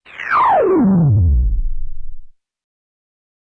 K-7 FX Down.wav